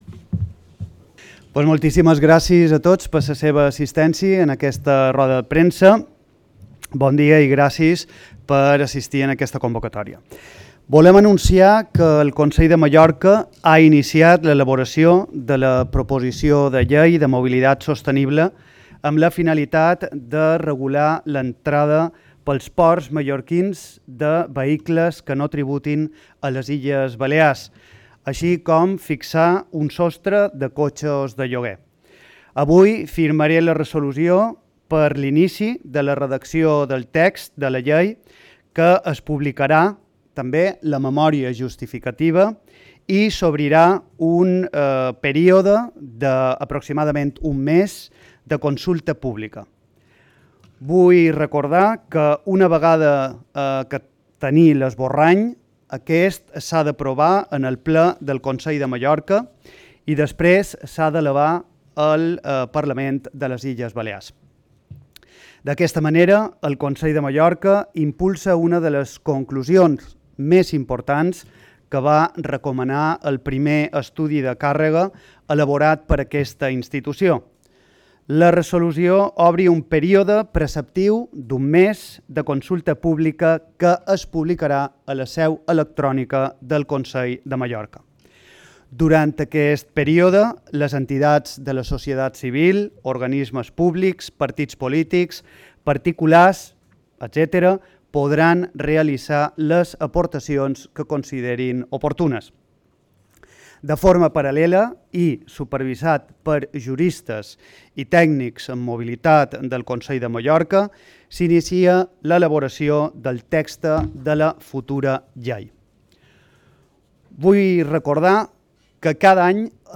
Podeis acceder a las declaraciones del consejero de Territorio, Movilidad e Infraestructuras, Fernando Rubio, clicando
declaracions-del-conseller-de-territori-mobilitat-i-infraestructures-fernando-rubio-mp3